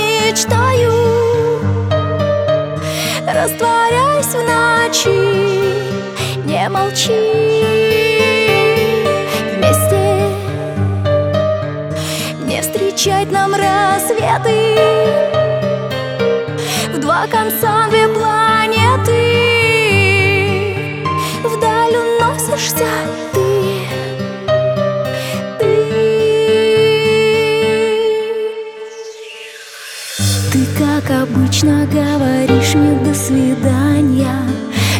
Жанр: Танцевальные / Русские
# Dance